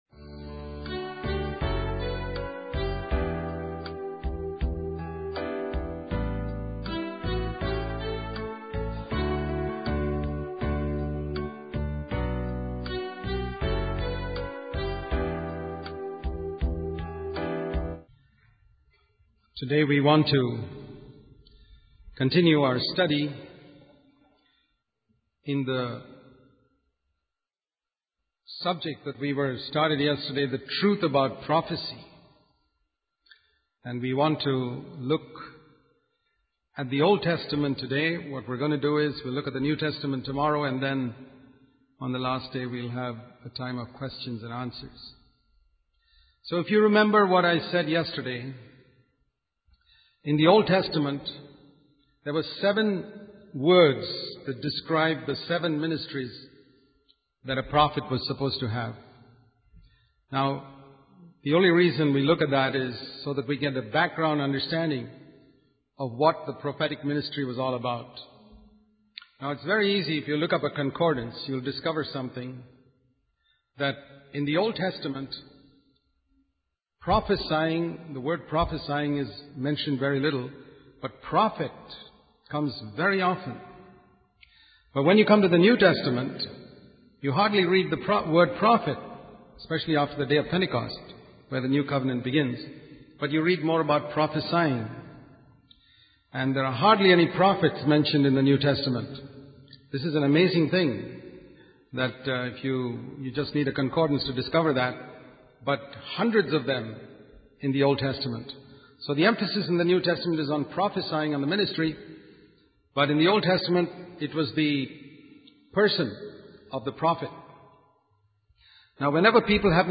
In this sermon, the preacher warns the audience about false prophets who are only interested in personal gain. He emphasizes the importance of being cautious and discerning when it comes to prophets who may be seeking money, sex, or power.